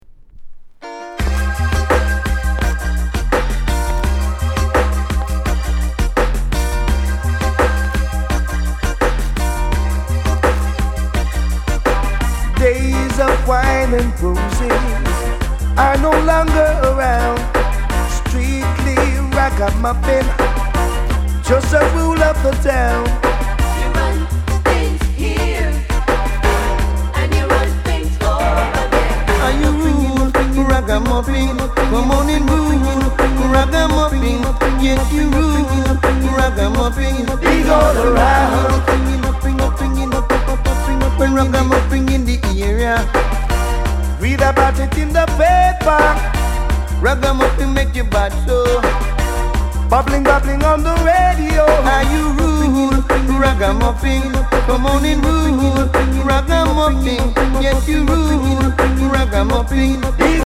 Ragamuffin